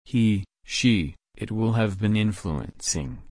/ˈɪn.flu.əns/